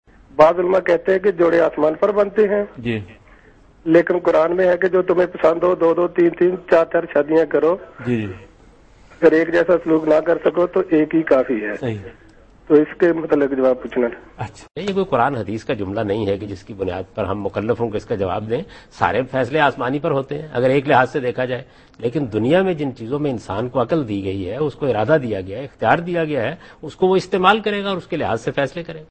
Javed Ahmad Ghamidi answers a question regarding "Guidance in the Quran about Marriage" in program Deen o Daanish on Dunya News.
جاوید احمد غامدی دنیا نیوز کے پروگرام دین و دانش میں قرآن میں مرد و عورت کے مابین شادی سے متعلق رہنمائی سے متعلق ایک سوال کا جواب دے رہے ہیں۔